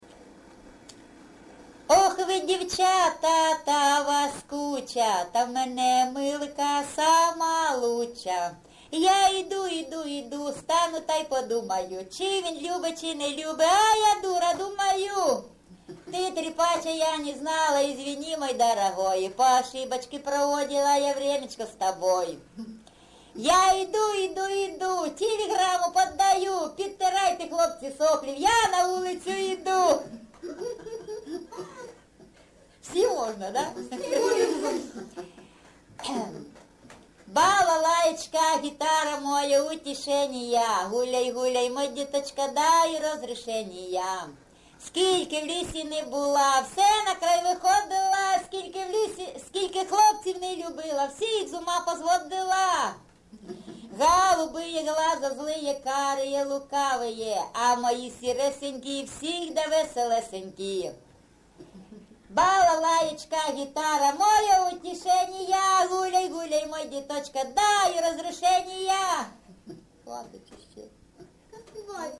ЖанрЧастівки
Місце записум. Сіверськ, Артемівський (Бахмутський) район, Донецька обл., Україна, Слобожанщина